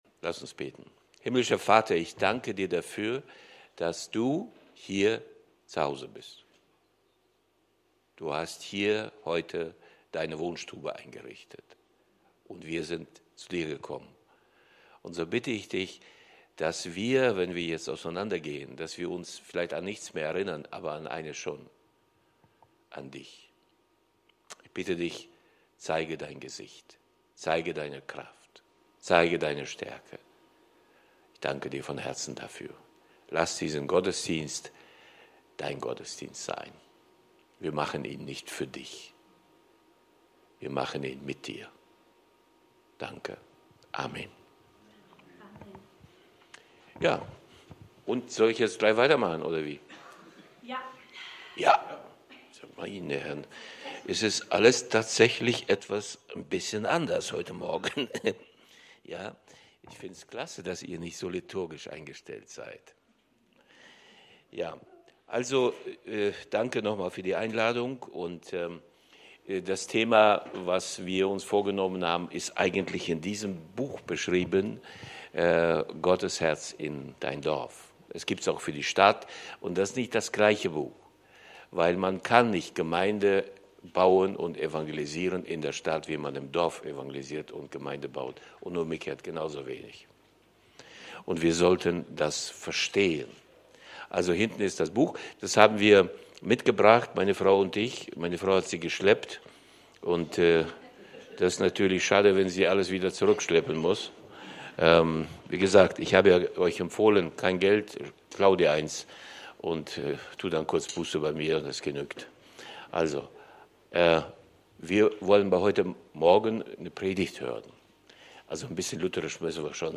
Serie: Predigten